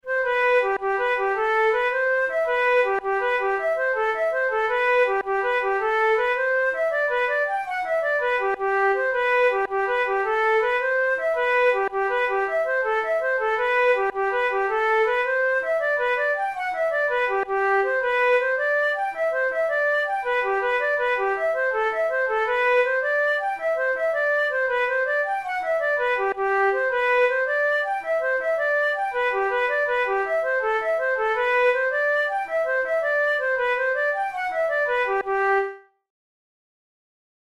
InstrumentationFlute solo
KeyG major
Time signature6/8
Tempo108 BPM
Jigs, Traditional/Folk
Traditional Irish jig